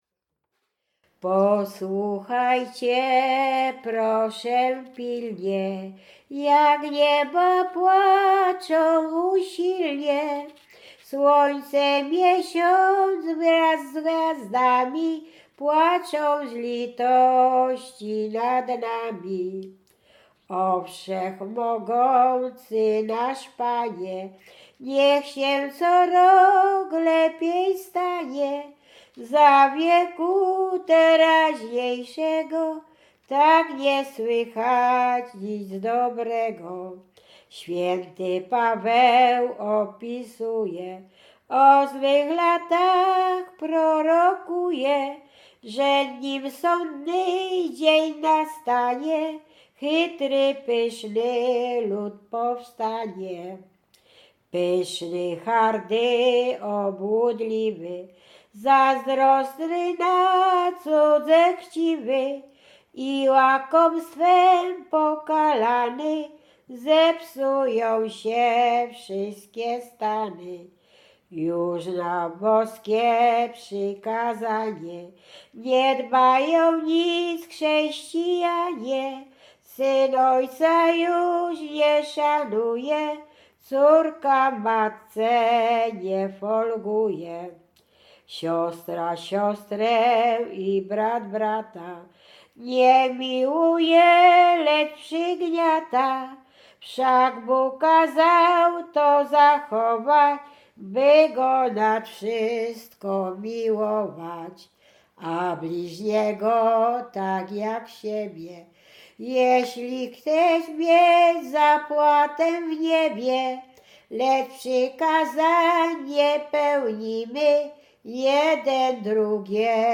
Łęczyckie
Pogrzebowa
pogrzebowe nabożne katolickie do grobu